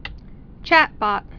(chătbŏt)